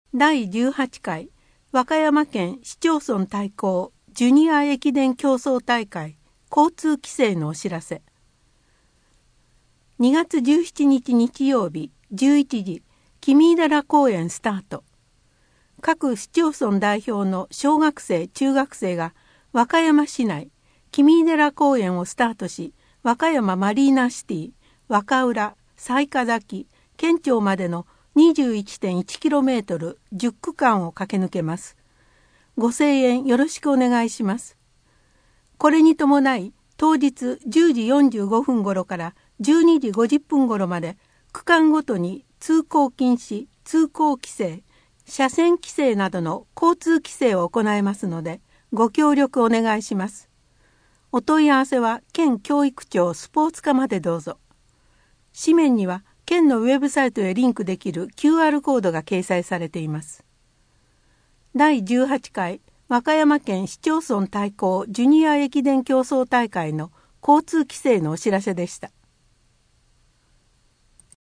「声の県民の友」はボランティア団体「和歌山グループ声」の皆さんのご協力により作成されています。